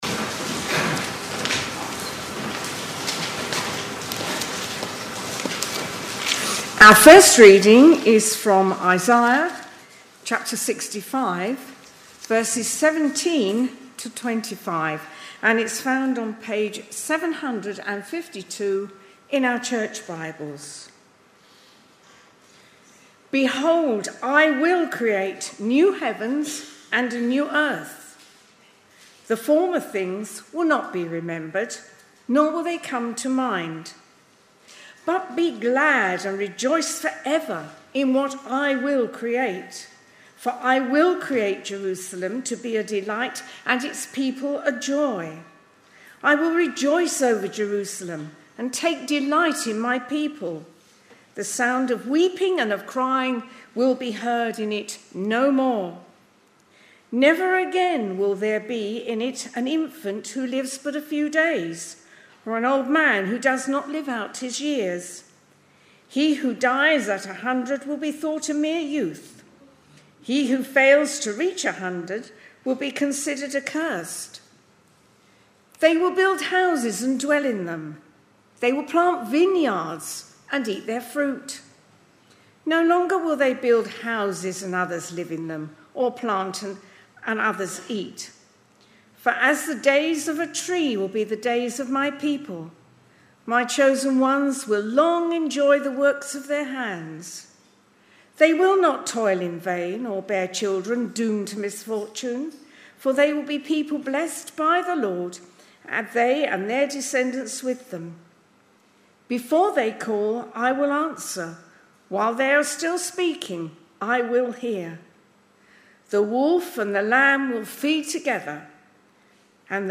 Readings-Sermon-on-1st-June-2025.mp3